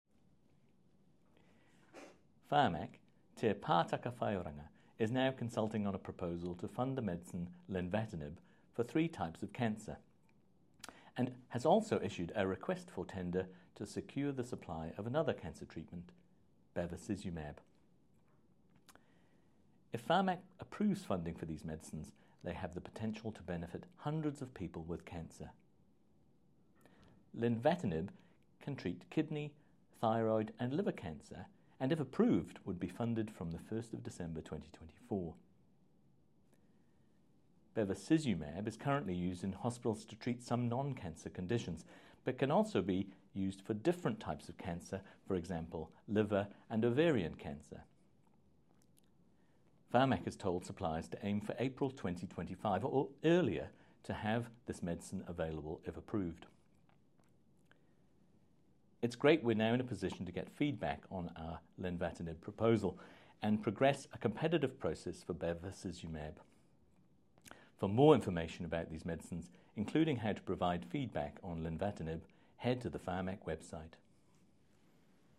• Lenvatinib: Len-va-tih-nib
• Bevacizumab: Beh-vuh-si-zuh-mab